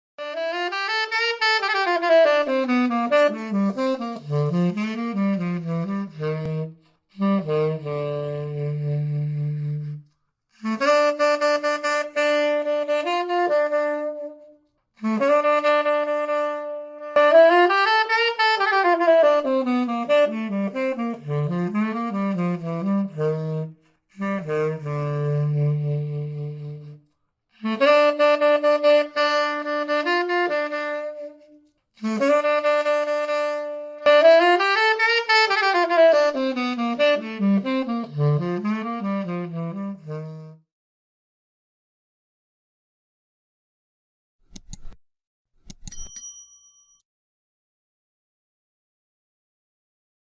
Sonido+De+Saxofon (audio/mpeg)
SAXOFÓN familia: viento madera